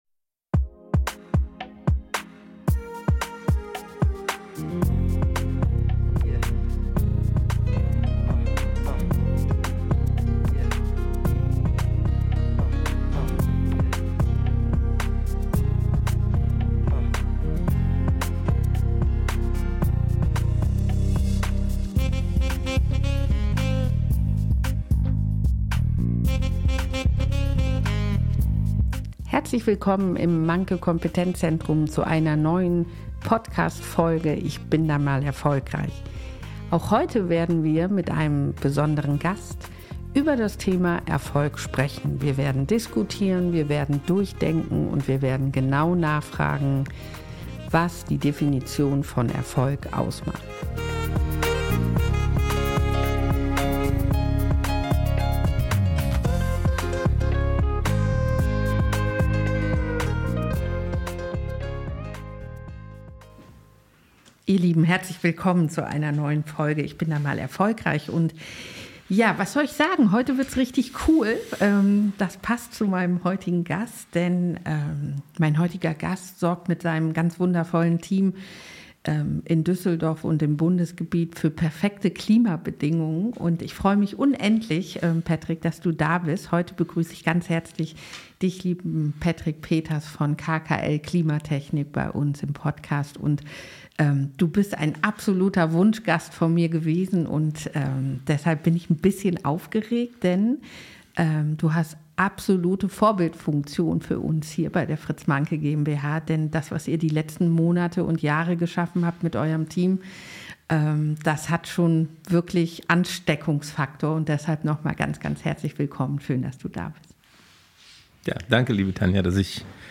Diese Episode ist ein Gespräch über Führung, Haltung, Menschlichkeit – und darüber, warum es gut ist, wenn nicht alles perfekt geplant ist.